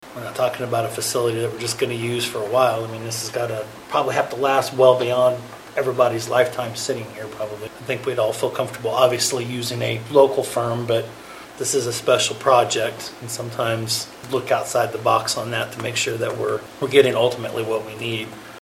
Commissioner John Ford says it’s beneficial to work with a firm with this kind of experience.